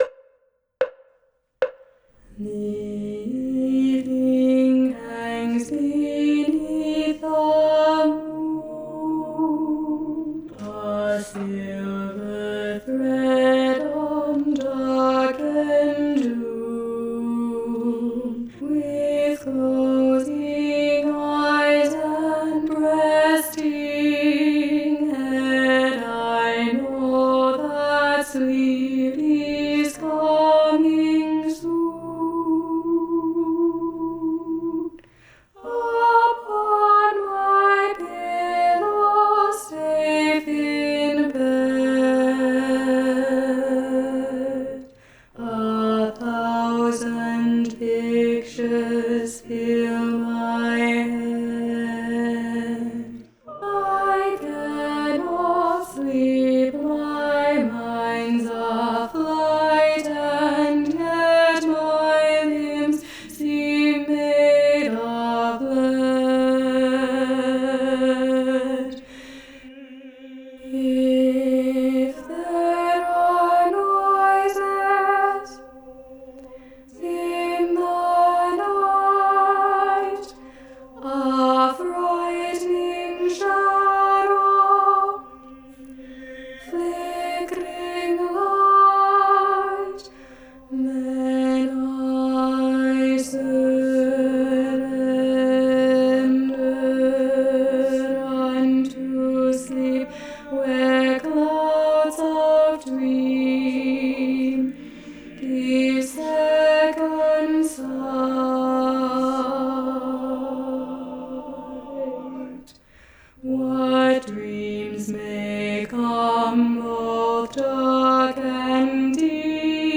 - Œuvre pour chœur à 8 voix mixtes (SSAATTBB)
Alto 2 Live Vocal Practice Track